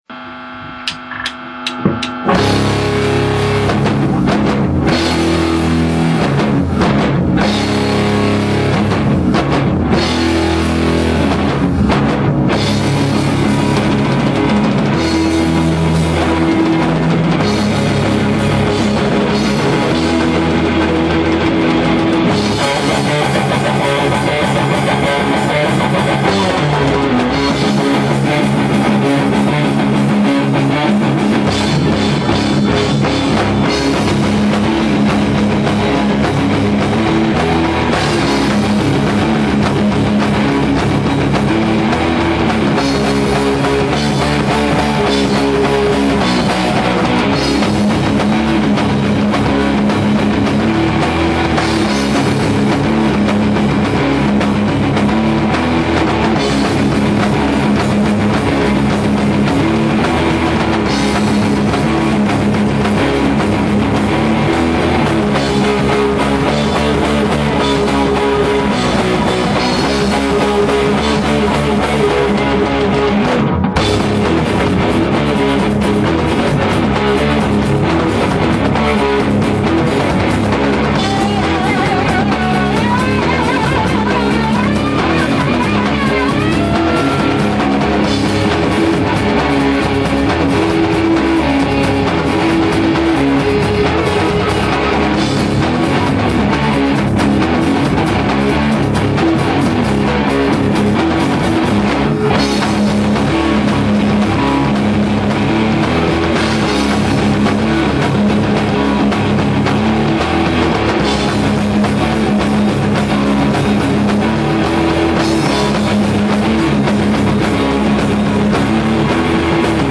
Gatunek: Rock